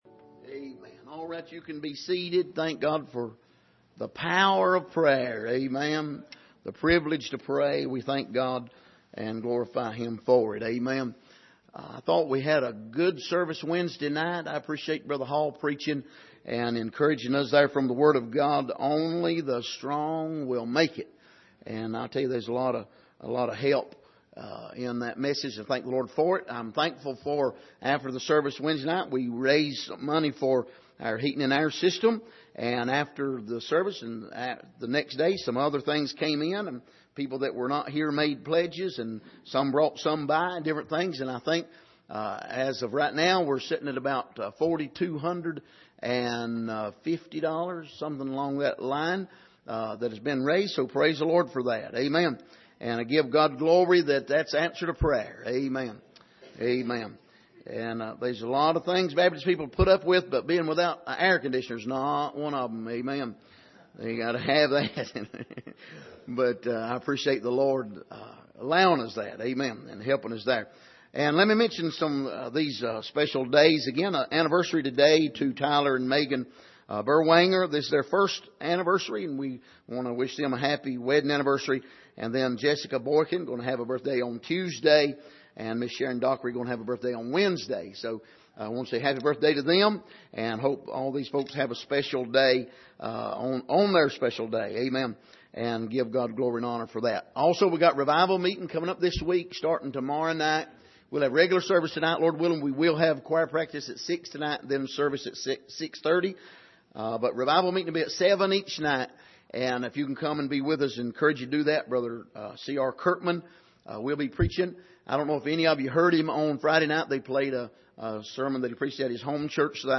Passage: Revelation 1:1 Service: Sunday Morning